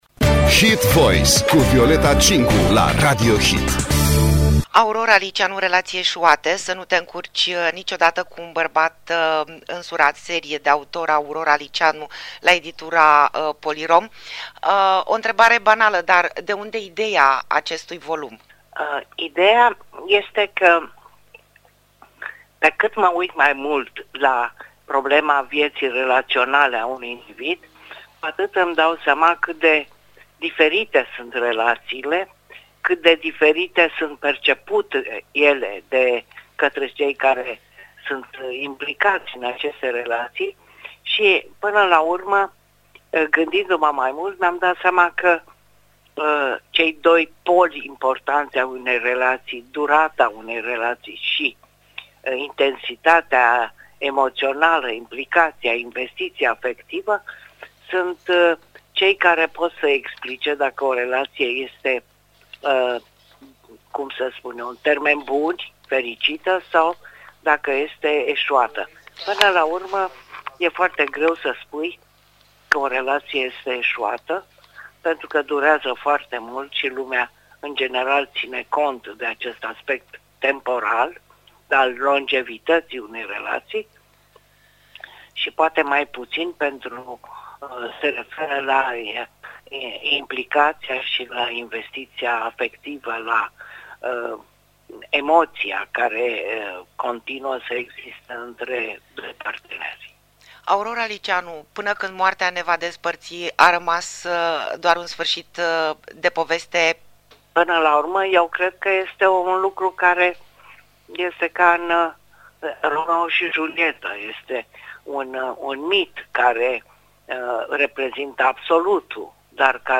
De ce să nu te încurci niciodată cu un bărbat însurat? Interviu cu Aurora Liiceanu - Radio Hit